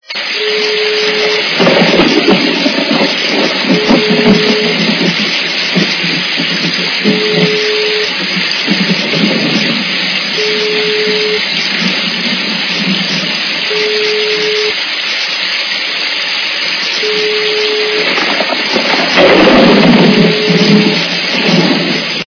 » Звуки » Природа тварини » Звук - Гроза или шум дождя
При прослушивании Звук - Гроза или шум дождя качество понижено и присутствуют гудки.
Звук Звук - Гроза или шум дождя